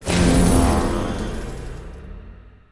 Some powerup/startup sound experiments
44khz IMA 4bit mono.